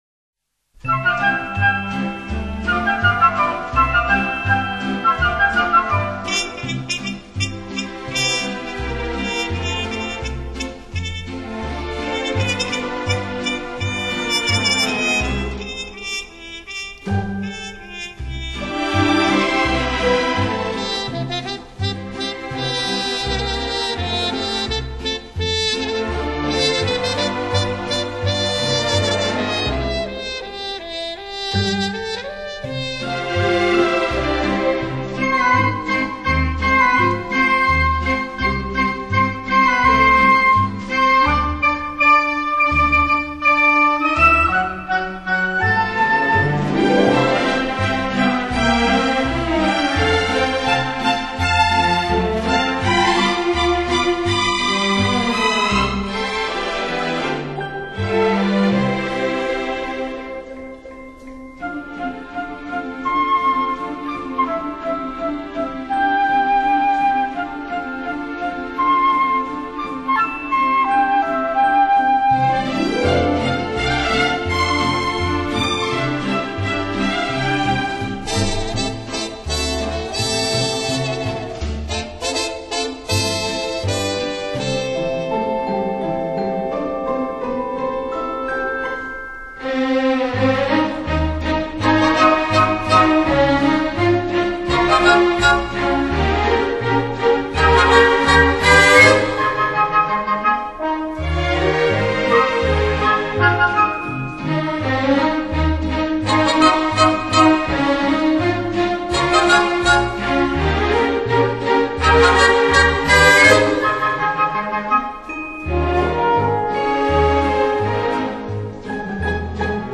畅舒展，旋律优美、动听，音响华丽丰满。